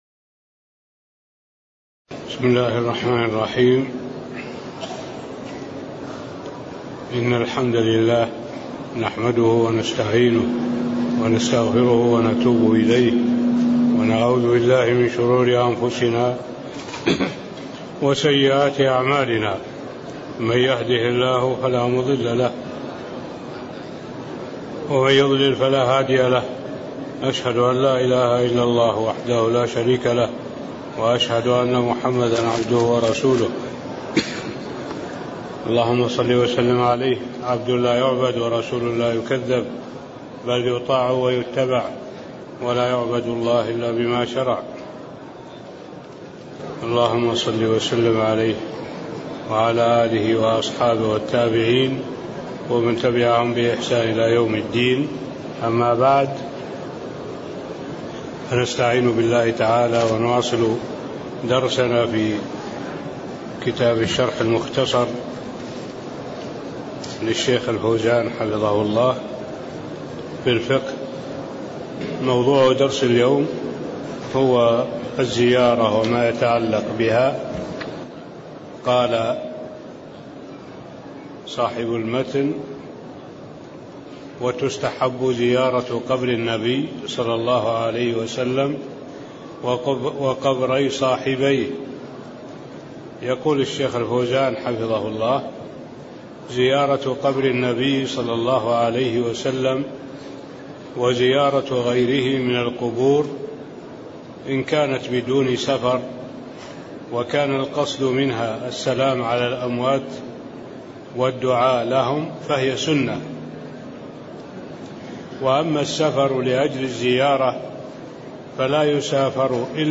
تاريخ النشر ٨ ذو القعدة ١٤٣٤ هـ المكان: المسجد النبوي الشيخ: معالي الشيخ الدكتور صالح بن عبد الله العبود معالي الشيخ الدكتور صالح بن عبد الله العبود من قوله: الزيارة وما يتعلّق بها (06)